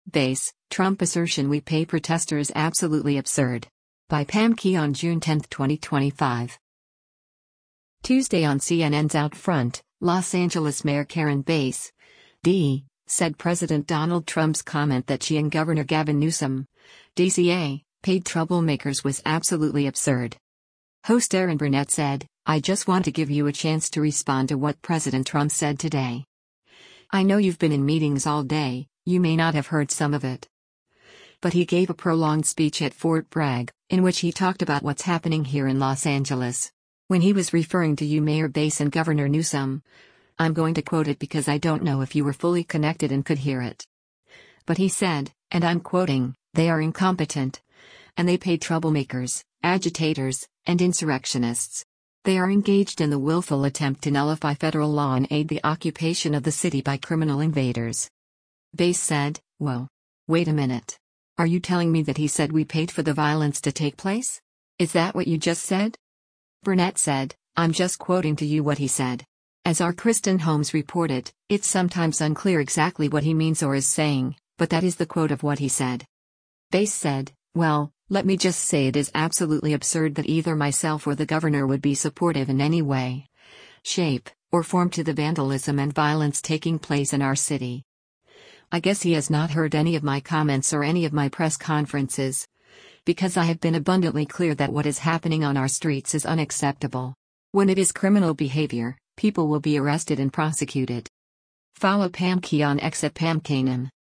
Tuesday on CNN’s “OutFront,” Los Angeles Mayor Karen Bass (D) said President Donald Trump’s comment that she and Gov. Gavin Newsom (D-CA) “paid troublemakers” was “absolutely absurd.”